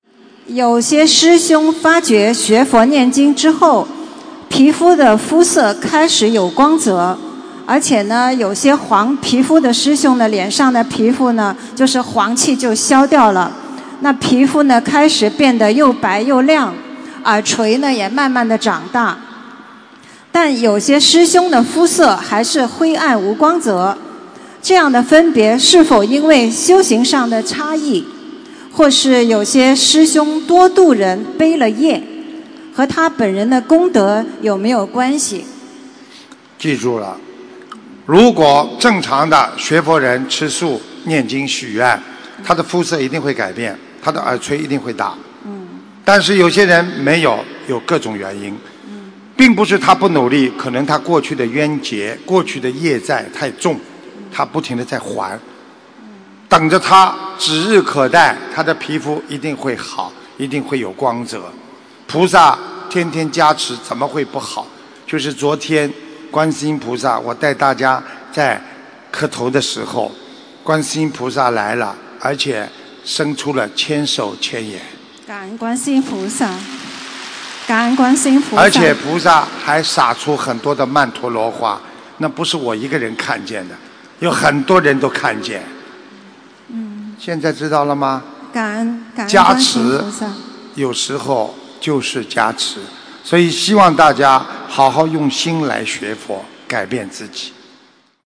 为何有人念经后肤色转好而有人没有┃弟子提问 师父回答 - 2017 - 心如菩提 - Powered by Discuz!